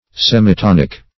Meaning of semitonic. semitonic synonyms, pronunciation, spelling and more from Free Dictionary.
Search Result for " semitonic" : The Collaborative International Dictionary of English v.0.48: Semitonic \Sem`i*ton"ic\, a. Of or pertaining to a semitone; consisting of a semitone, or of semitones.